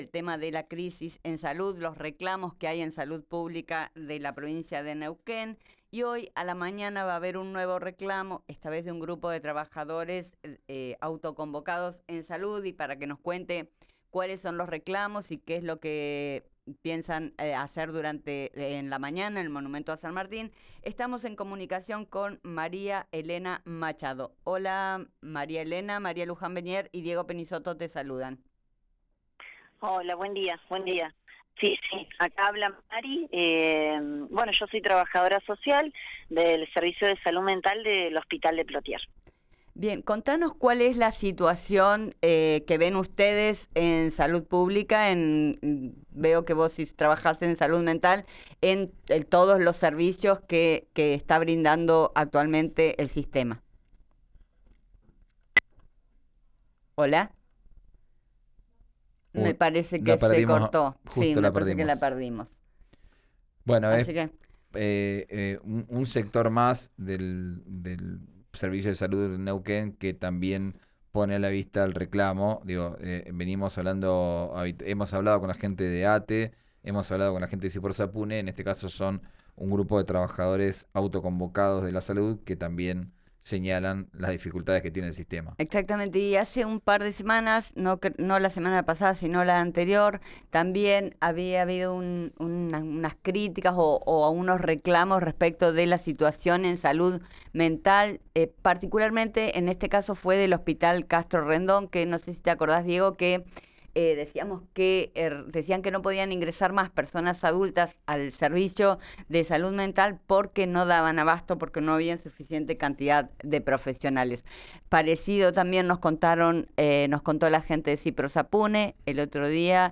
Escuchá a una de las trabajadoras autoconvocadas